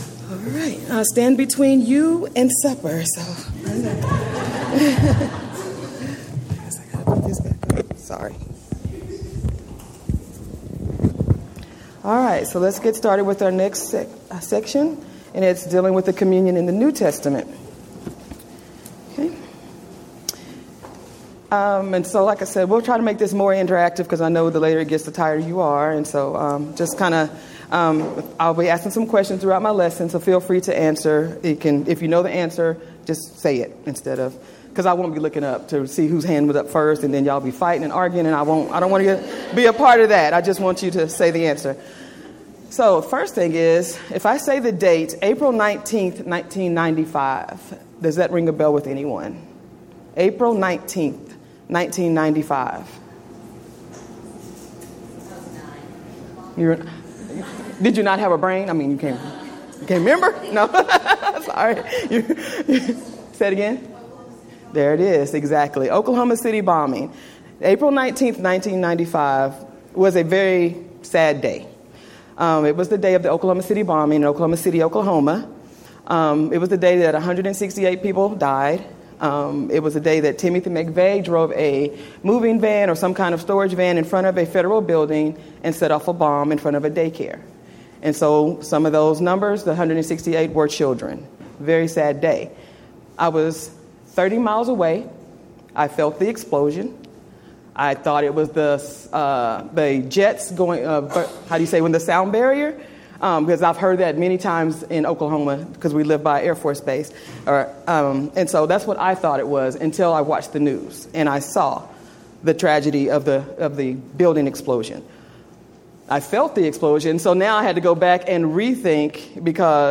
Event: 5th Annual Women of Valor Ladies Retreat
Ladies Sessions